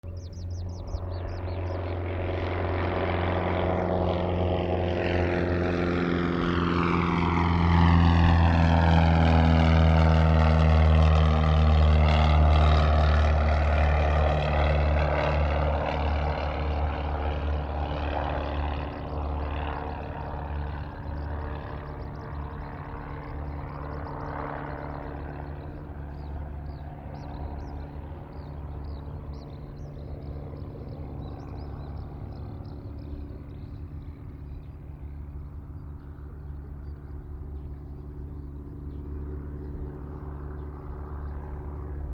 飛行機離陸
八尾空港 416 NT4